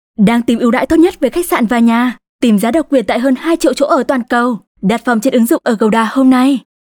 VIETNAMESE NORTH FEMALE VOICES
We use Neumann microphones, Apogee preamps and ProTools HD digital audio workstations for a warm, clean signal path.